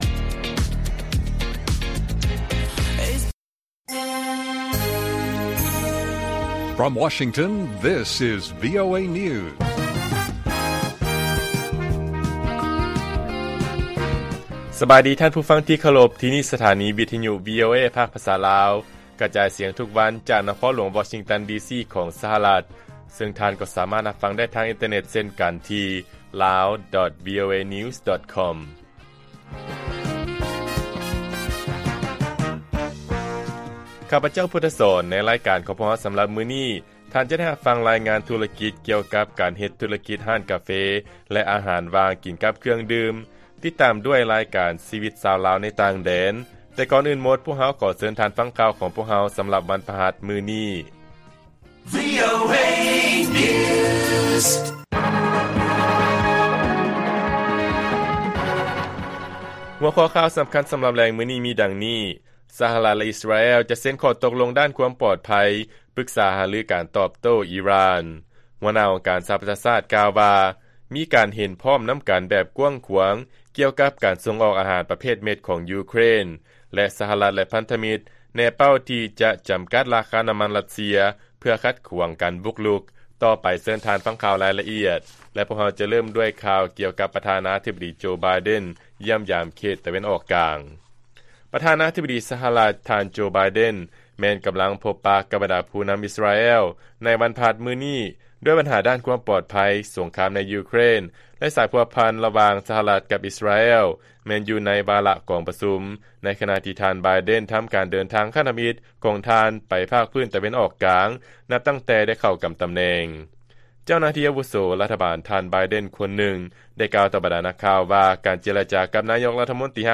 ລາຍການກະຈາຍສຽງຂອງວີໂອເອ ລາວ: ສະຫະລັດ ແລະ ອິສຣາແອລ ຈະເຊັນຂໍ້ຕົກລົງດ້ານຄວາມປອດໄພ, ປຶກສາຫາລືການຕອບໂຕ້ ອີຣ່ານ